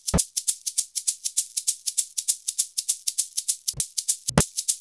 100SHAK03.wav